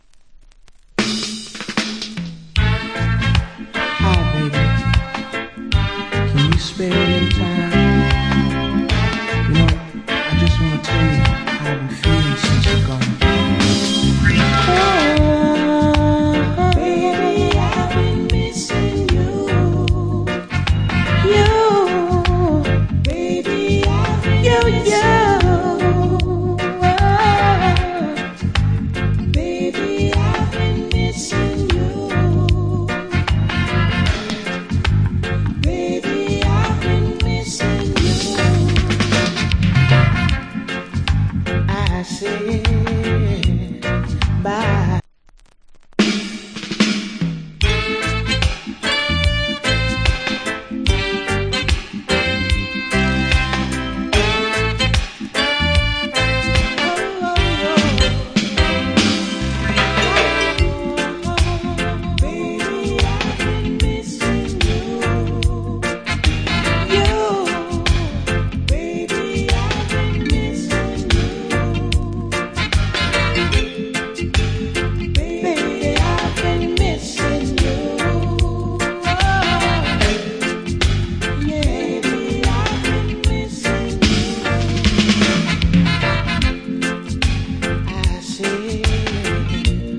Lovers Rock Vocal.